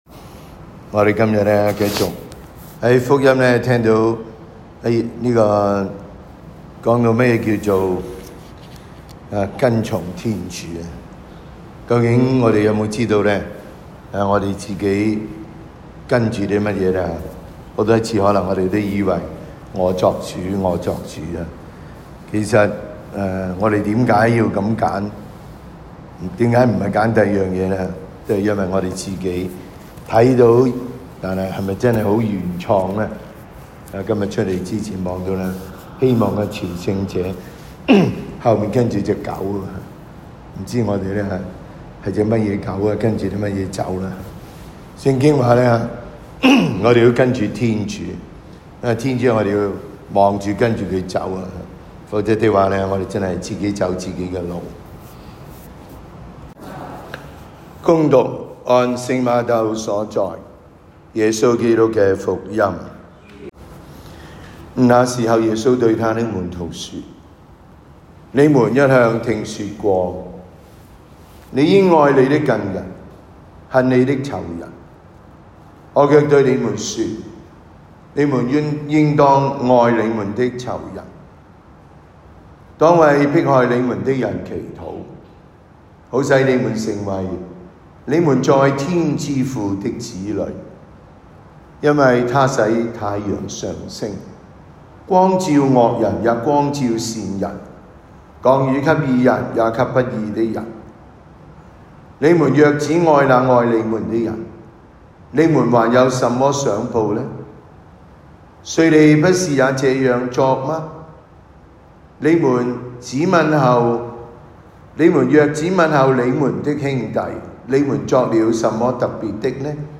感恩祭講道